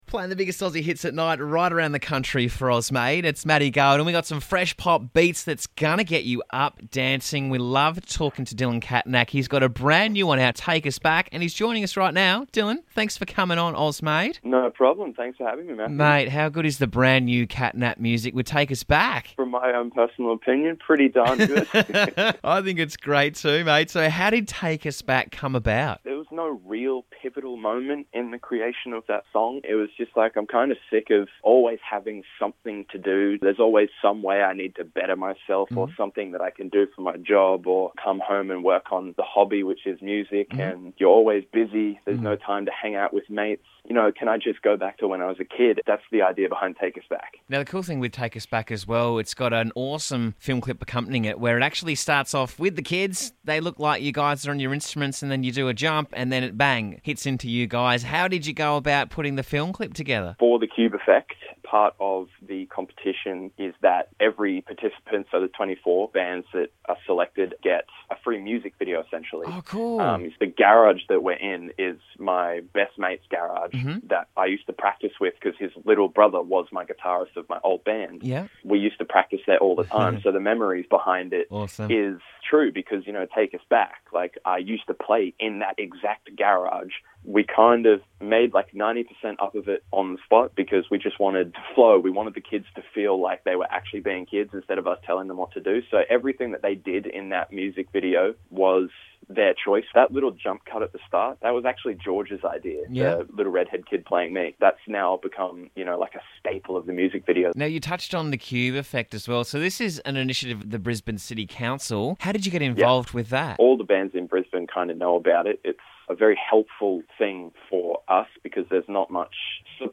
high energy
synth pop / indie rock tune